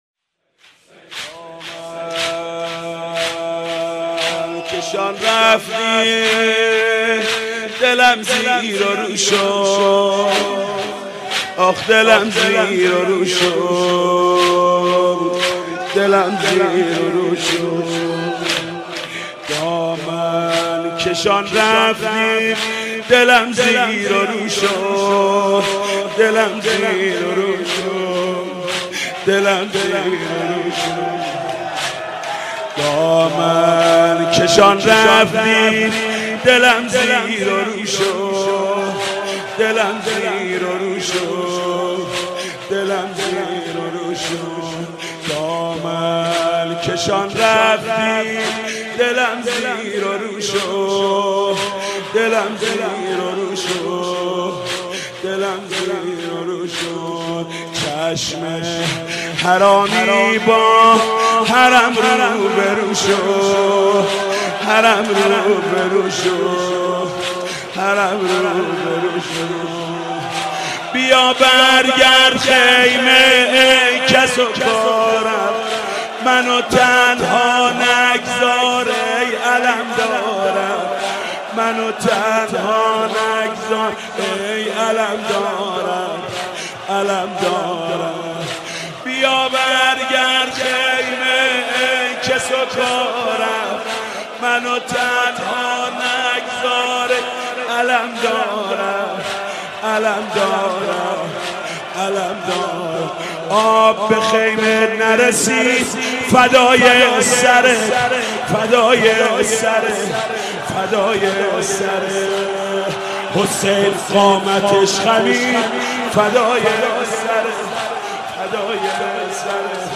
مداحی
پخش آنلاین نوحهدانلود نوحه با کیفیت 320